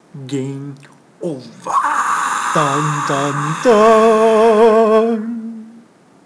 Game_Over_1.wav